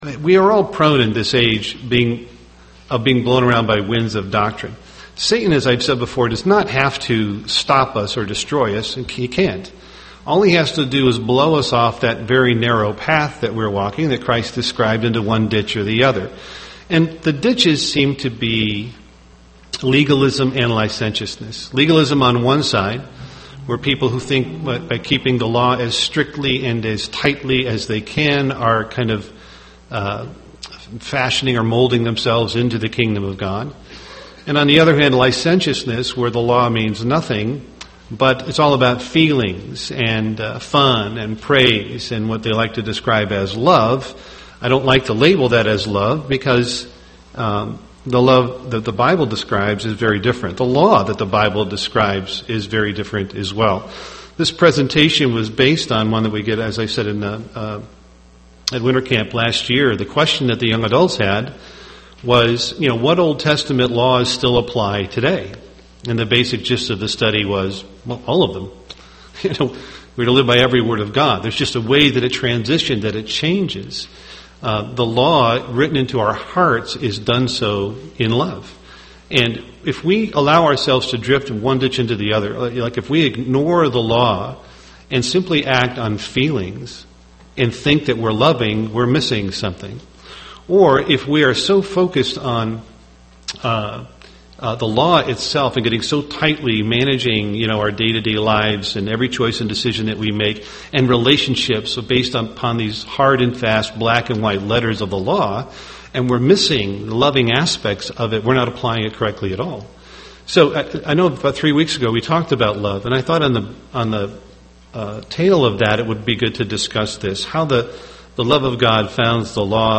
UCG Sermon law Grace truth Studying the bible?